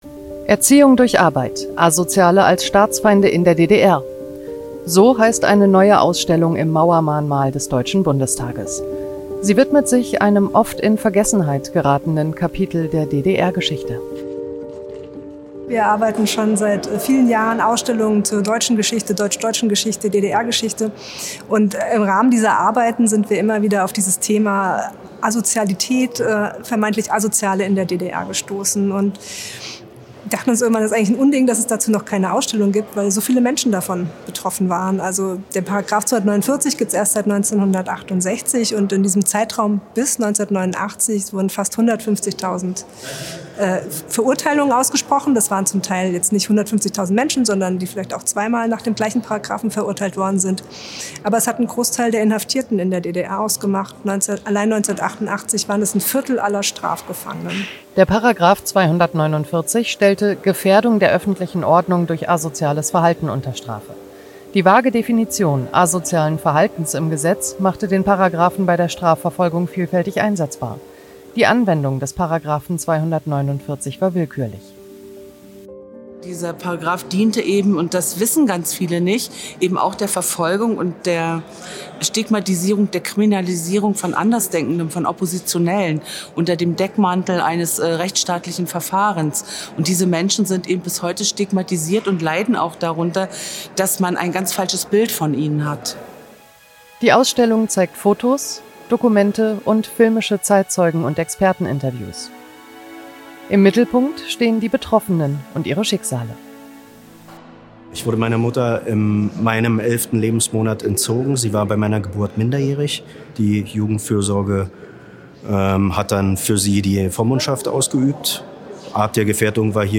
Evelyn Zupke eröffnet Ausstellung über „Asoziale“ als Staatsfeinde in der DDR
Ausstellungseröffnung „Erziehung durch Arbeit. ‚Asoziale‘ als Staatsfeinde in der DDR“